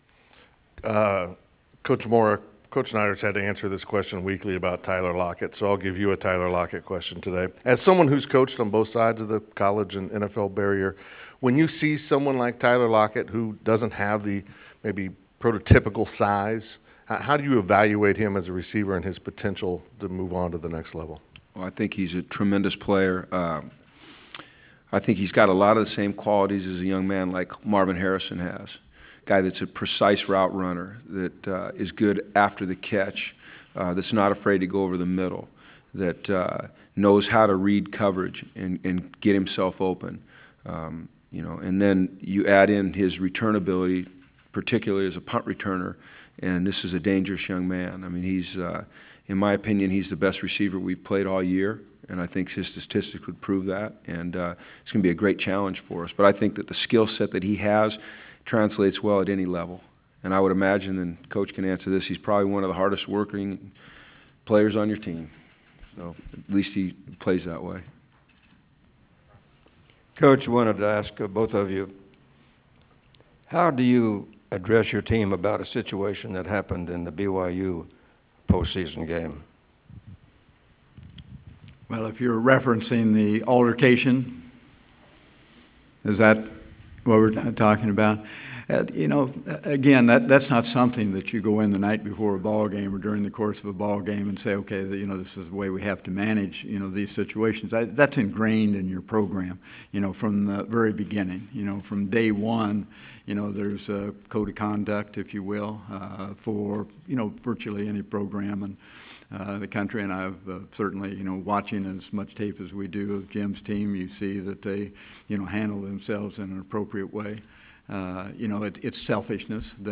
Head Coaches Press Conference Pt. 3
Kansas State head coach Bill Snyder and UCLA head coach Jim Mora.
ABowl-2014-Coaches-PC-Pt3.wav